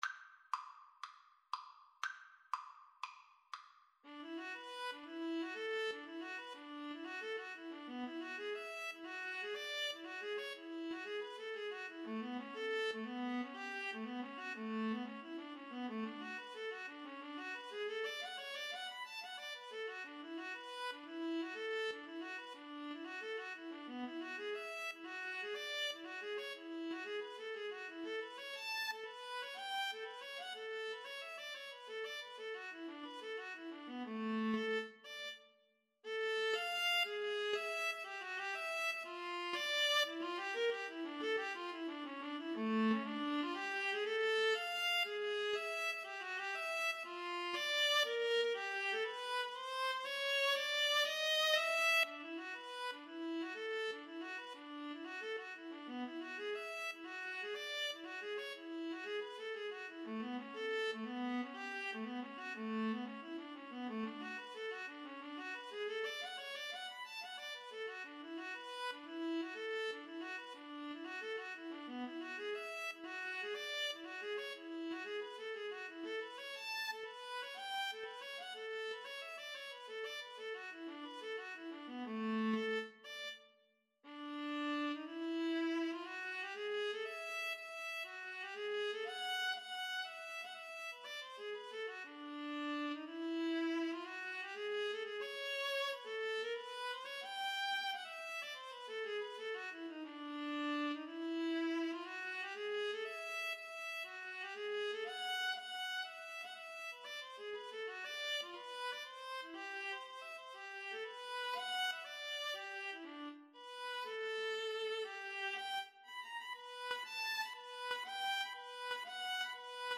Jazz (View more Jazz Viola Duet Music)
Rock and pop (View more Rock and pop Viola Duet Music)